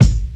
• Classic Raw Hip-Hop Bass Drum One Shot G Key 756.wav
Royality free kick drum sample tuned to the G note. Loudest frequency: 364Hz
classic-raw-hip-hop-bass-drum-one-shot-g-key-756-Rvw.wav